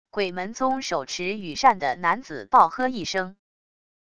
鬼门宗手持羽扇的男子暴喝一声wav音频